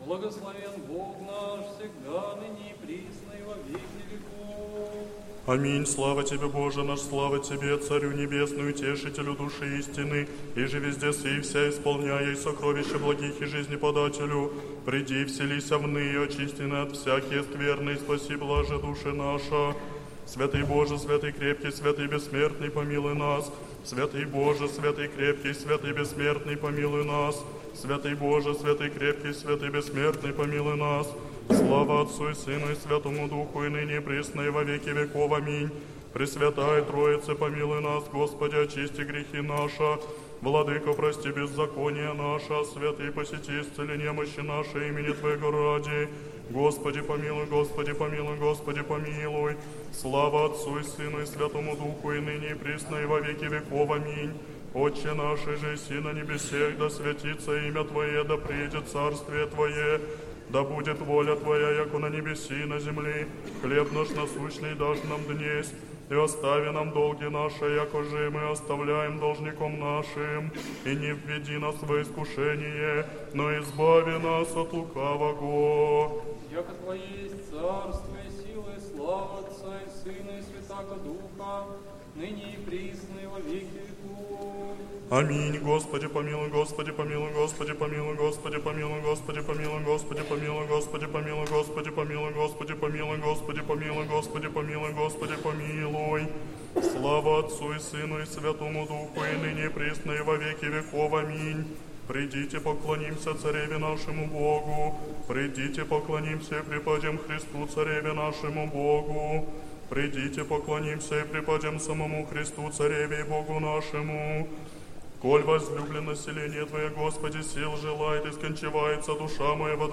Вечерня с чином прощения в Сретенском монастыре
Вечерня, чин прощения. Хор Сретенского монастыря.